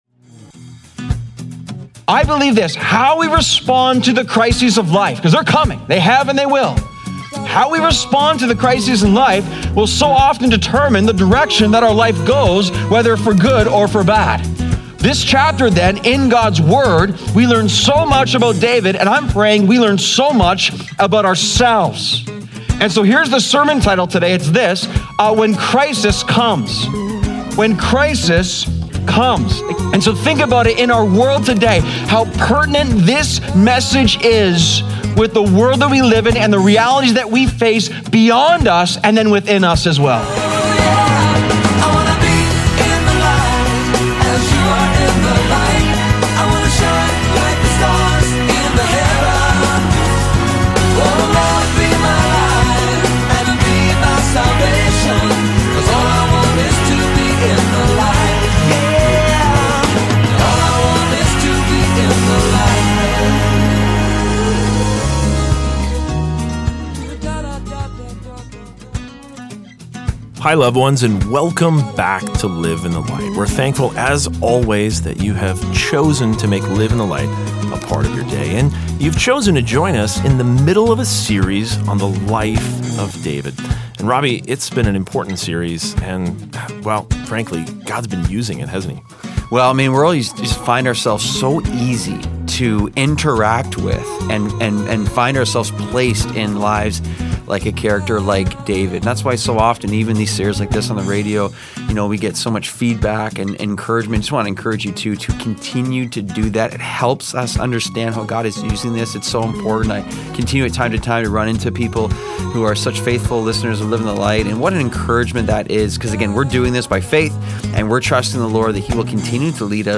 Daily Broadcast